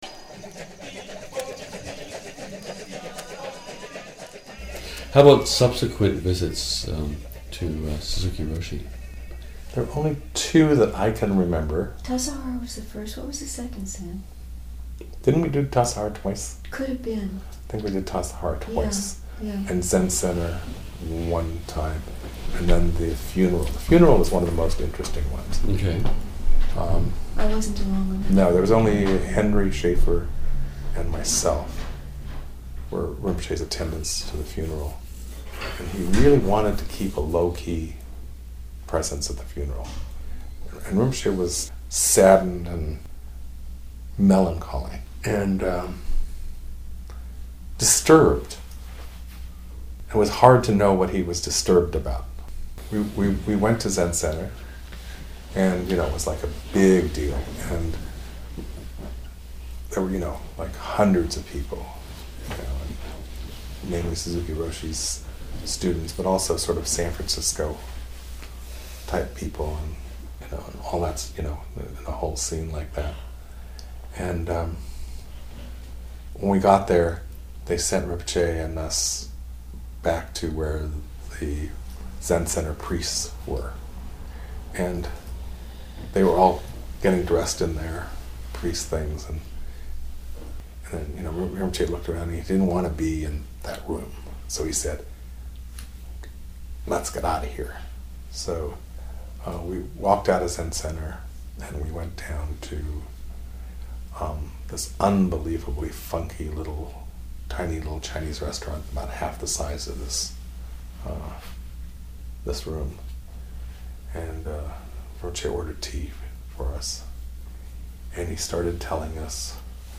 This clip is excerpted from a longer conversation, recorded in 2004, in Boston.